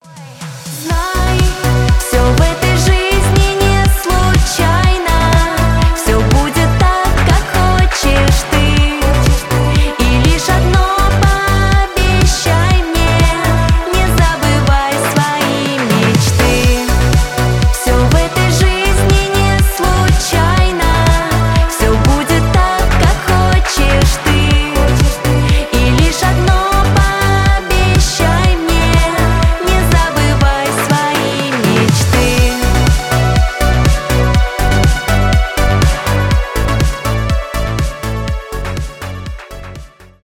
диско
synth pop